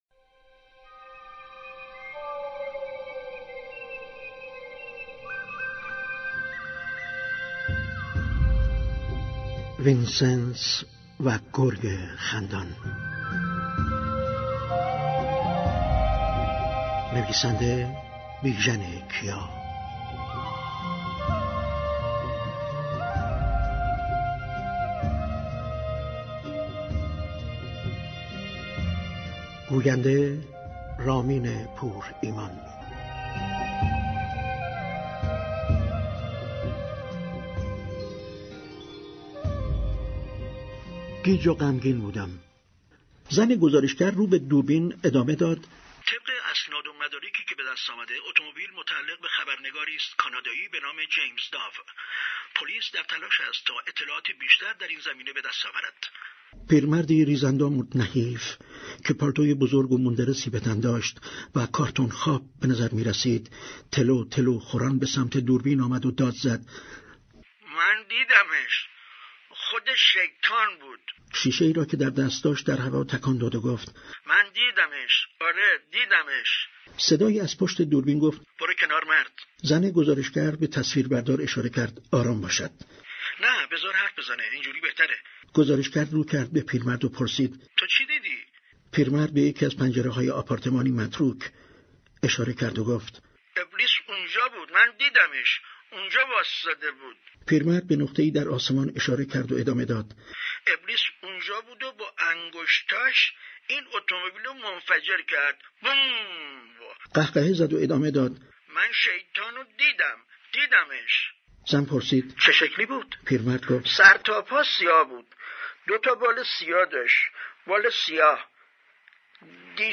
از روز پنج شنبه ، هفتم تیرماه ، پخش كتاب گویای وینسنس و گرگ خندان با موضوع